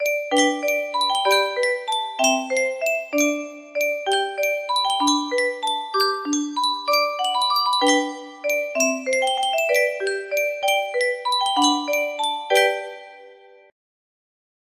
Yunsheng Music Box - Amazing Grace 038Y music box melody
Full range 60